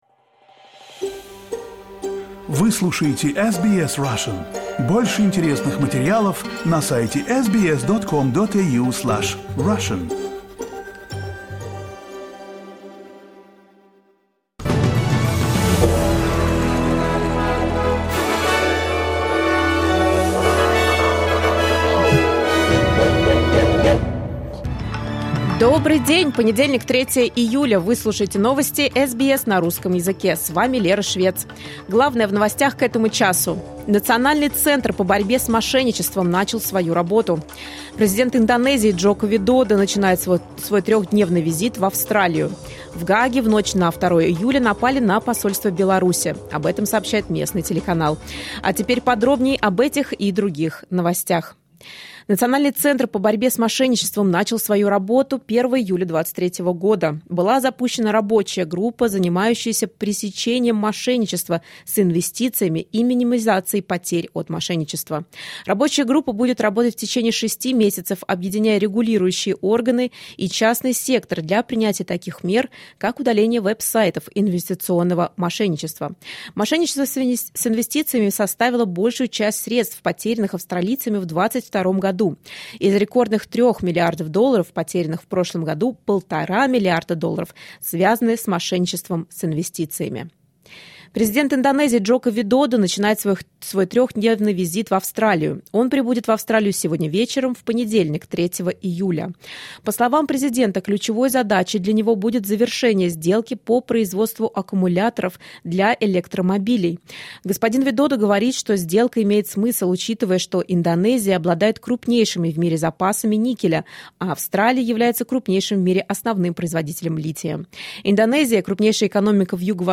SBS news in Russian — 03.07.2023